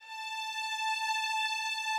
strings_069.wav